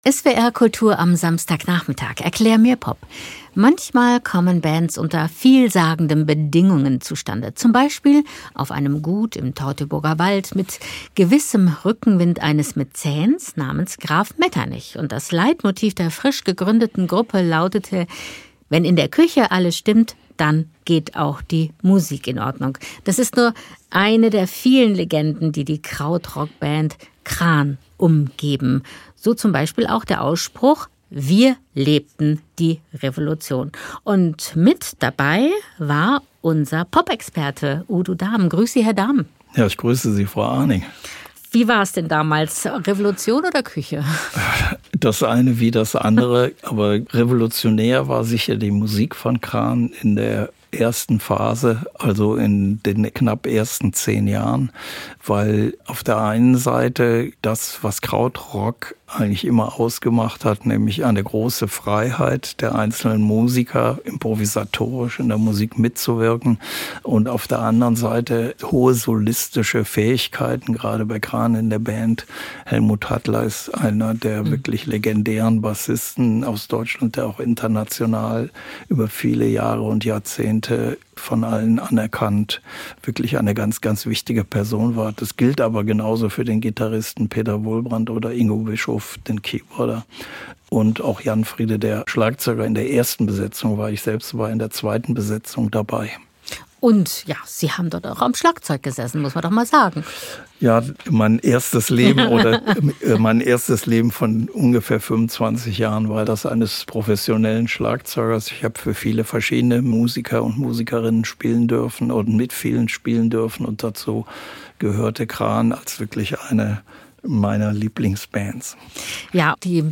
Stilistisch bewegt sie sich zwischen Rock, Soul und einer jazzigen Spielweise, sie integriert dabei Funkelemente und sphärische Klänge.
Gespräch mit